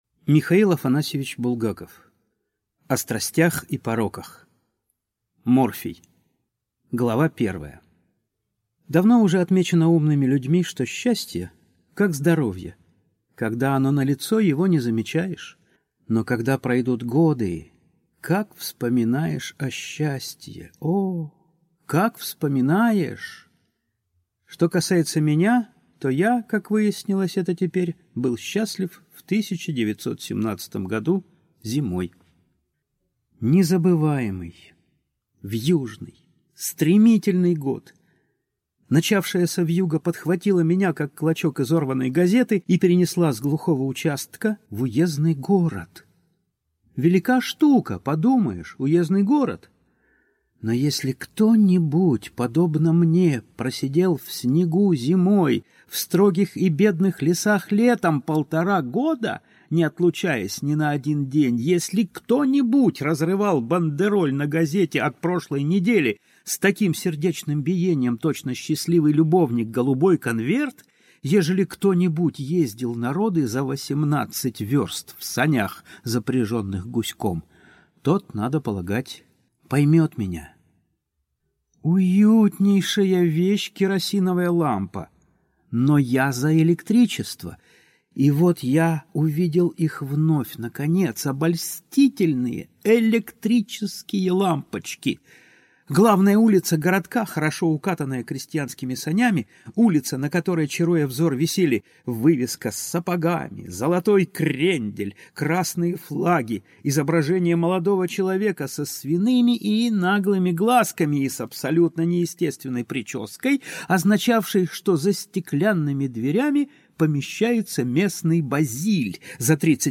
Аудиокнига О страстях и пороках (сборник) | Библиотека аудиокниг